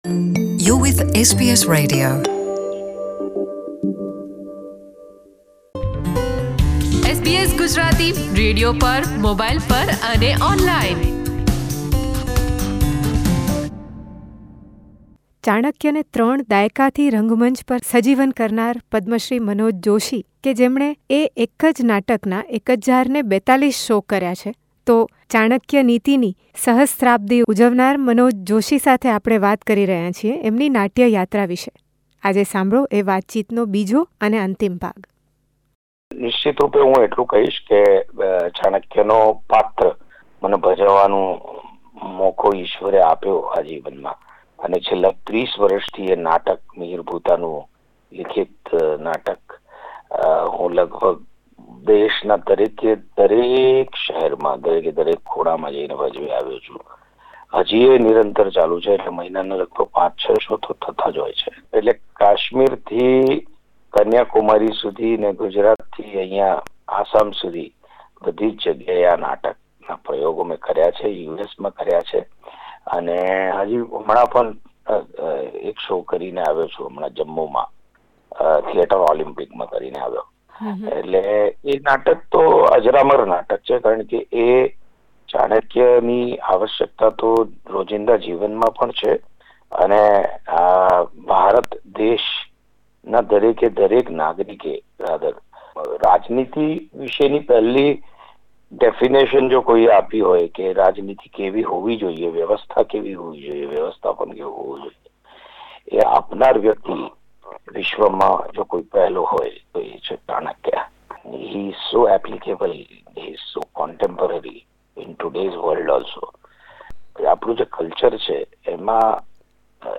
Reliving 'Chanakya' for the last thirty years on stages across India, Manoj Joshi is a versatile Gujarati actor. In this second and last part of his conversation with SBS Gujarati, he shares his experience of playing six diverse characters including one female role, in a single play 'Rangilo', a Gujarati comedy.